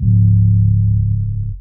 TM88 Mudd808.wav